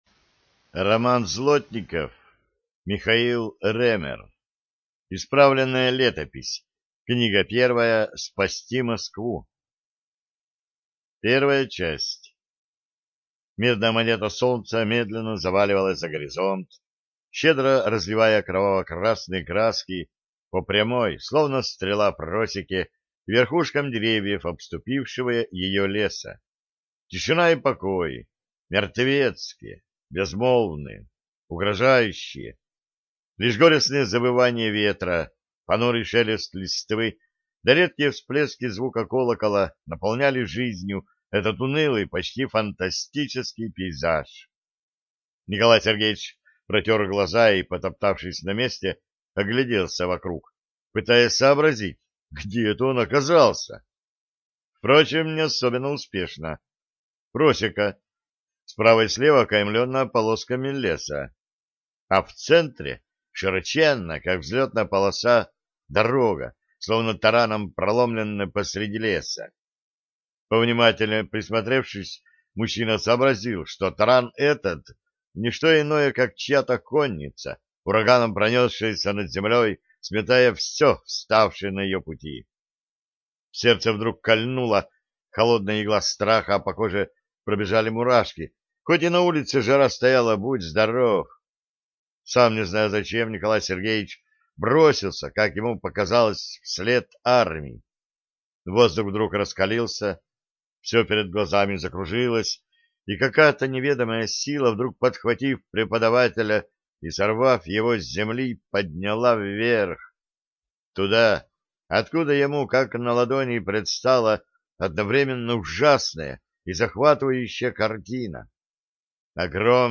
ЖанрАльтернативная история